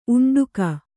♪ uṇḍuka